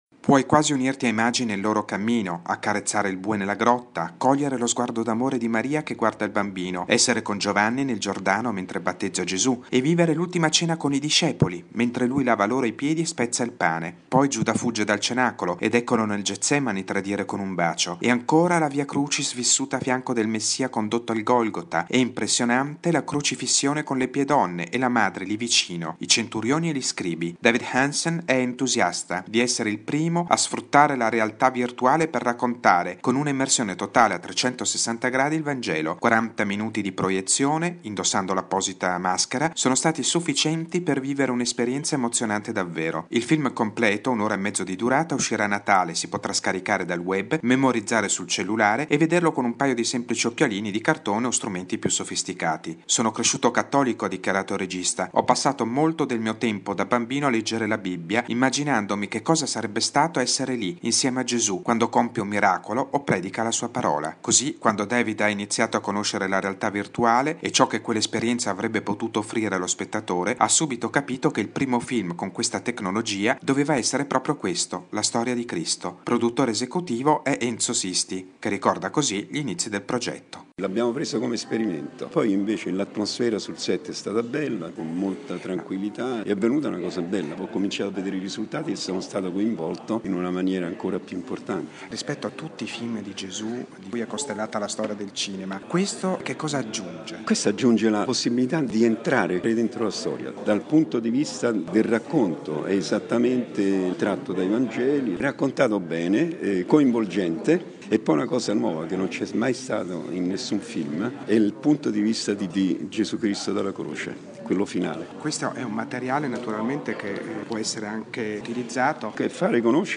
Sono stati presentati alla Mostra del Cinema di Venezia sei episodi tratti dal film "Jesus VR - The Story of Christ", diretto da David Hansen: un'esperienza emozionante di partecipazione visiva, grazie alla realtà virtuale, che immerge per la prima volta lo spettatore nei luoghi e nei momenti più famosi della narrazione evangelica. Il servizio